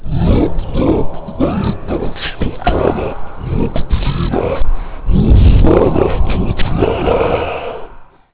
chant.wav